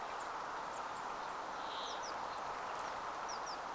viherpeipon järripeippomatkinta??
olisiko_viherpeipon_jarripeippomatkinta.mp3